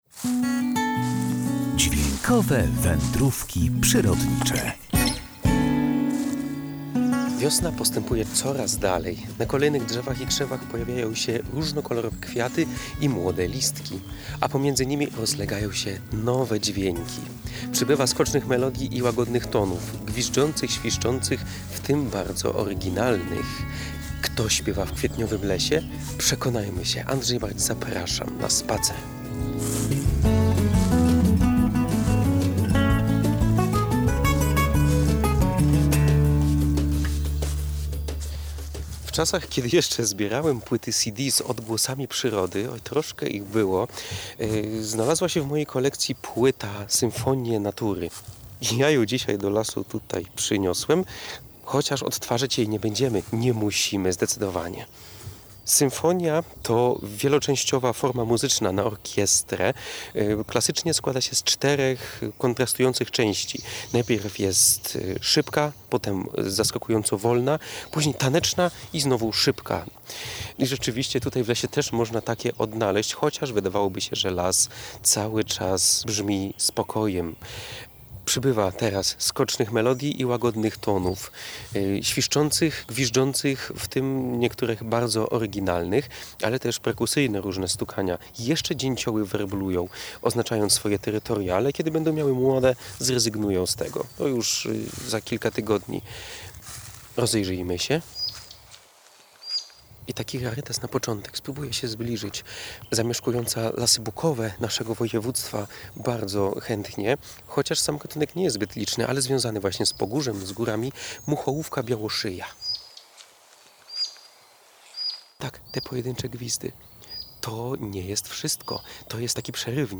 Na kolejnych drzewach i krzewach pojawiają się różnokolorowe kwiaty i młode listki, a pomiędzy nimi rozlegają się nowe dźwięki.
Leśna symfonia
Przybywa skocznych melodii i łagodnych tonów – gwiżdżących, świszczących, w tym bardzo oryginalnych.